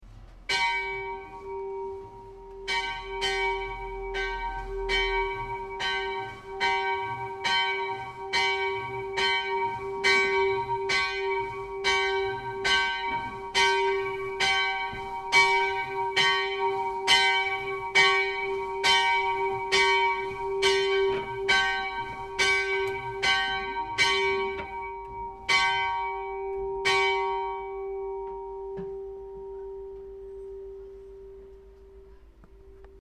Die Glocken von Mondsee klingen so
Mondsee_Glocke_5_MP3